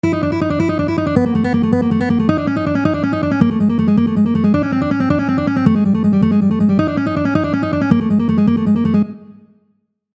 To make the buzzing sound of a bee, it is necessary to gradually increase the speed until the desired effect is achieved.
A 6-note pattern warm-up that alternates between 2 strings
Notice that the accented notes are the first notes in the pattern.
Here's what it sounds like - (160 bpm)
buzzing-sound-1.mp3